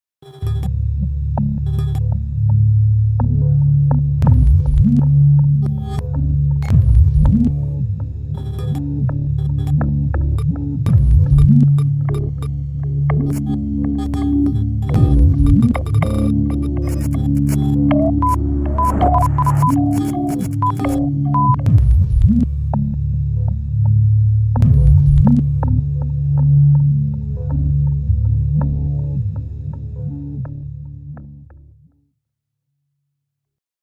Bande son d'une vidéo réalisée pour un projet "blitz" (48h) ayant pour thème le temps du suspens dans les films d'action holywoodiens. Synthèse, prise de son et montage.